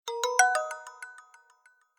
Звуки телефона Lenovo